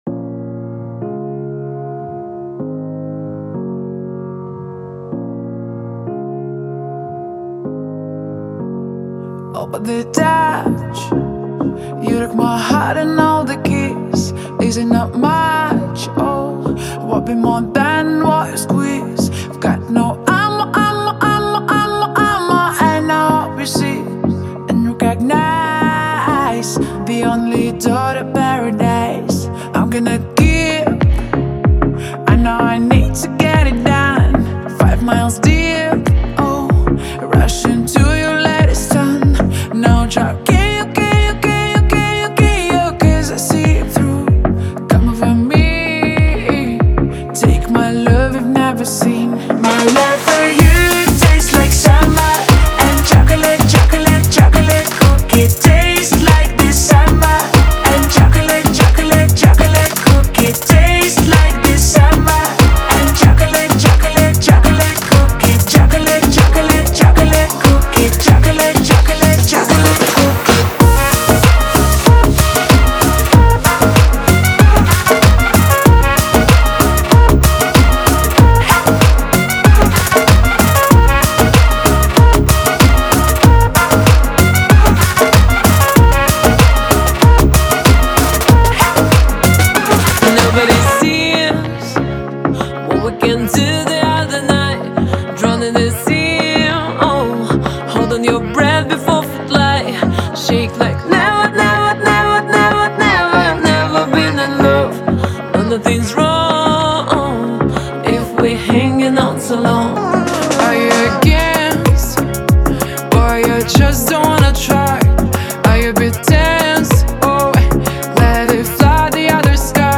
Главная » Файлы » Поп Музыка Категория